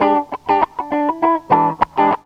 GTR 60 EM.wav